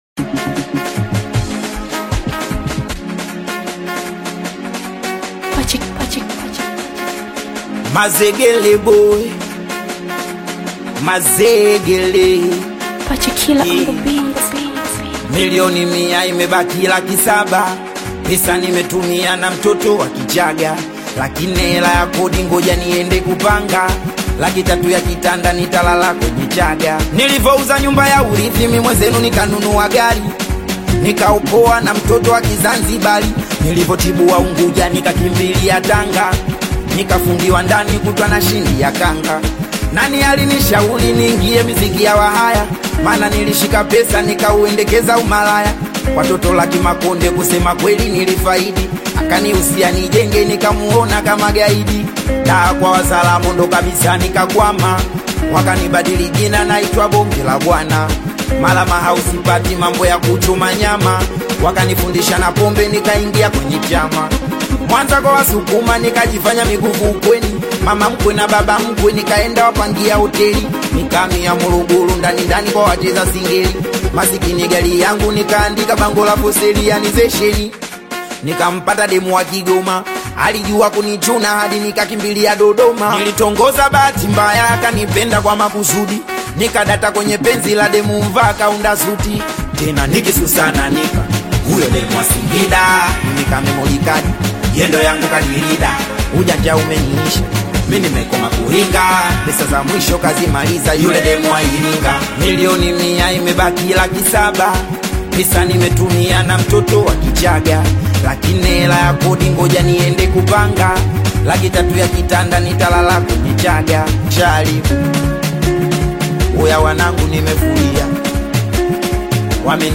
high-energy Singeli-inspired single
Genre: Singeli